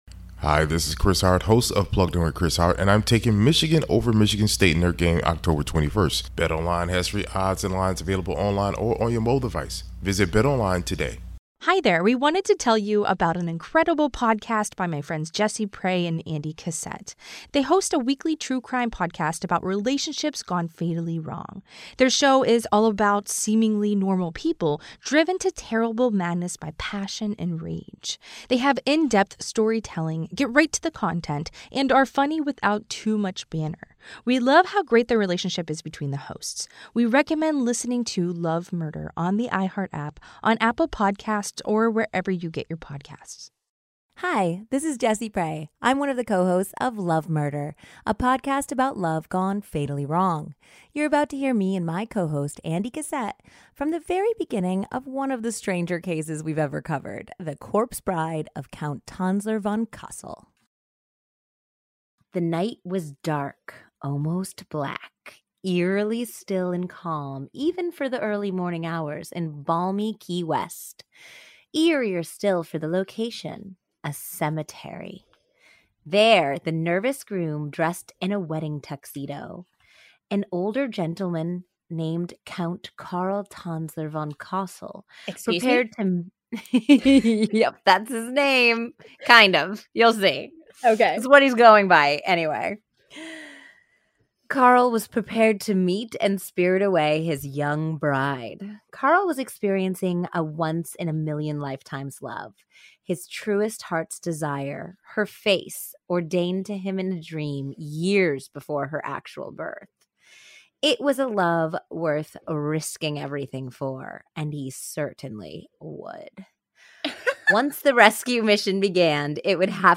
Their show is all about seemingly normal people driven to terrible madness by passion and rage. They have in-depth storytelling , get right into the content, and are funny without too much banter.